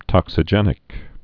(tŏksə-jĕnĭk)